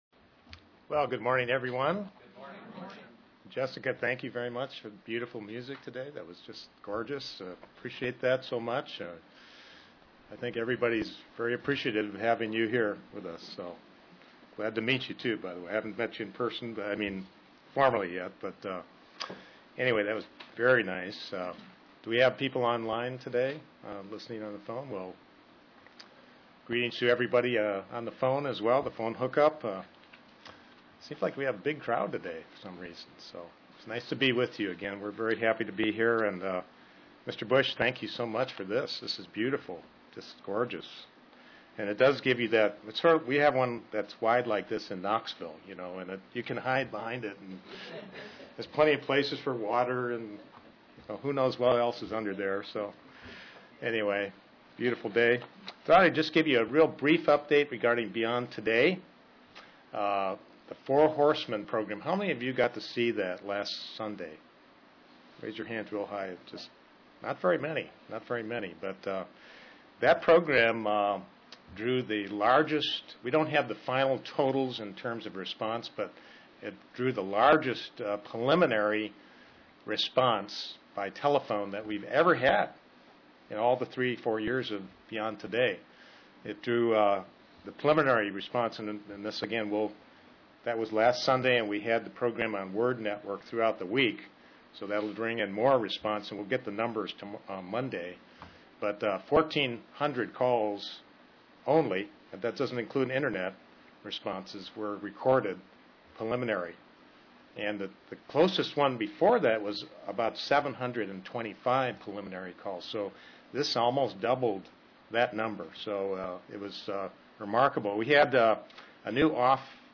Print Satan deceives the world UCG Sermon Studying the bible?